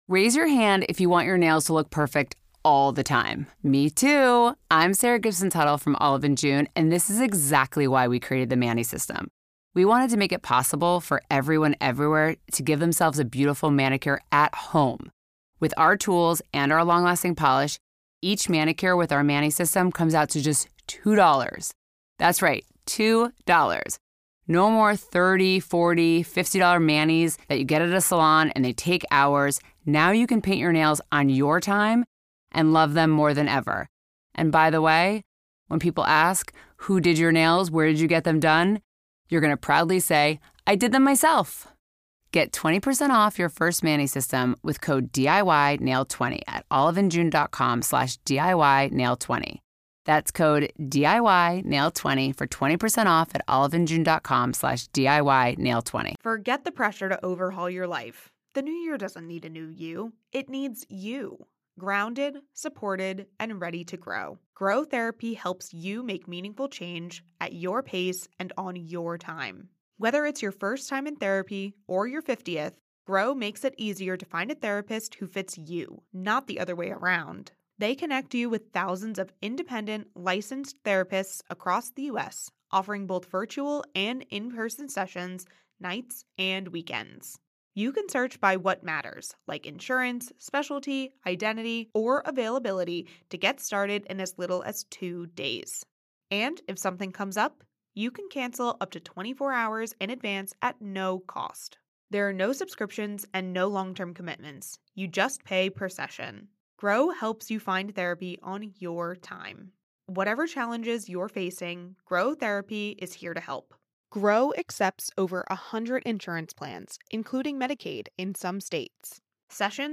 interviews guests discussing the topic of staying God-centered...both replacing "me" with "He" and remembering we are centered in Him.